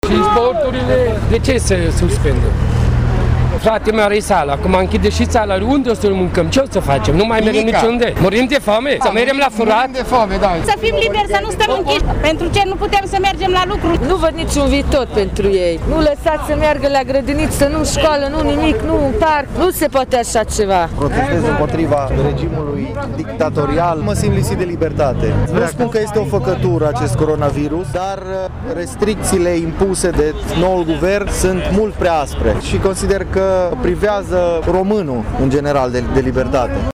Circa 50 de persoane au protestat în fața Prefecturii Mureș
De asemenea, protestatarii s-au arătat îngrijorați de faptul că restricțiile impuse îi afectează profund în plan economic: